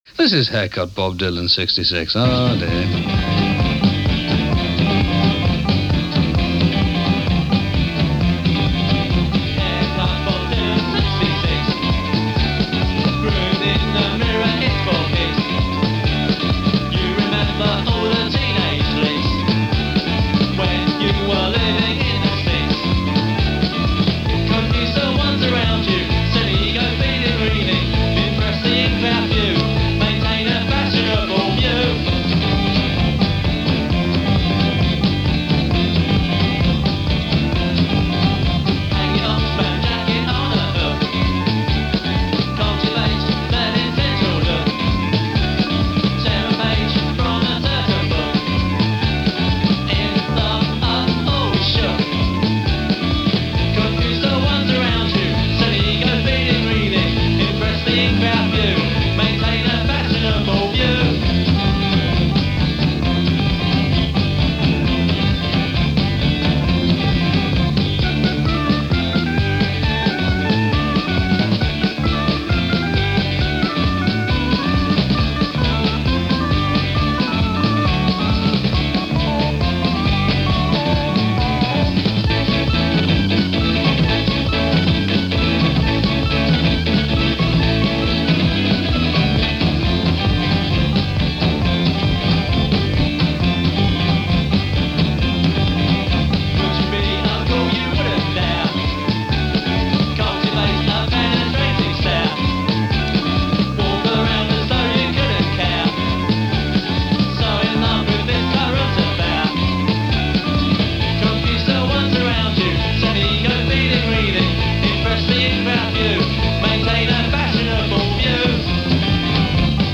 post-punk band